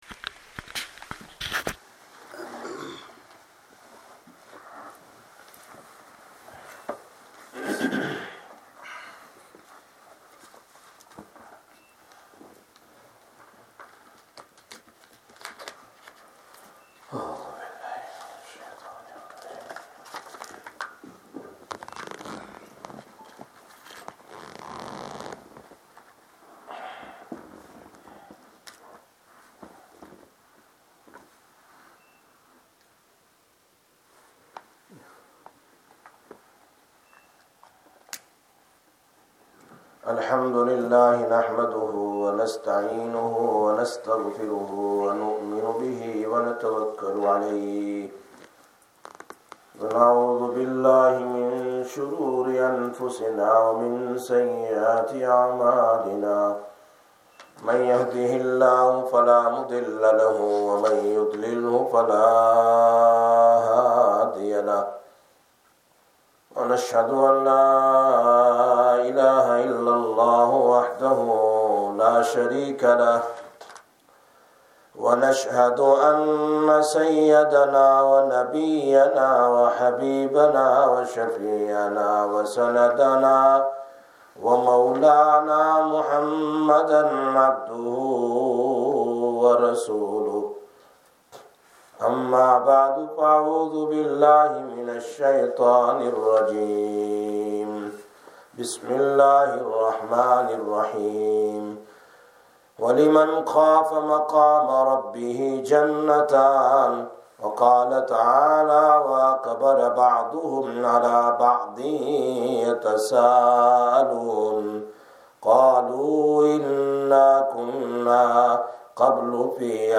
08/02/17 – Sister Bayan, Masjid Quba